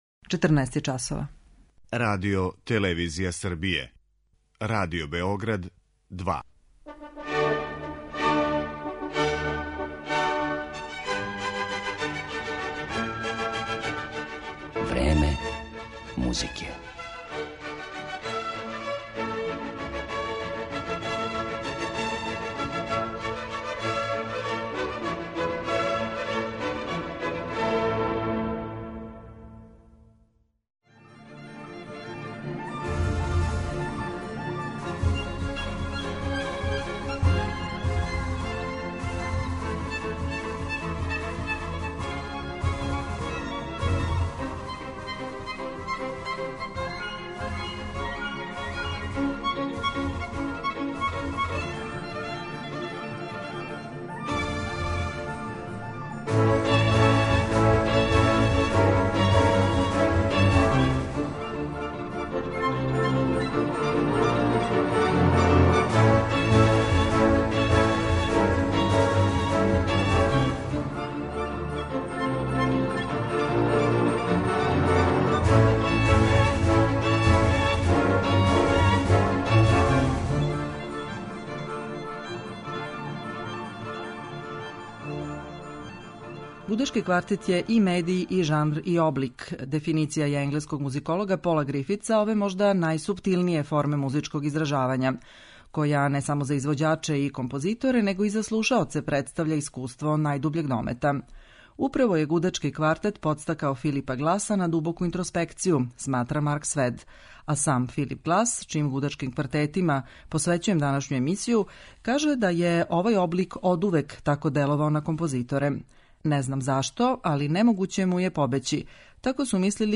Гудачки квартети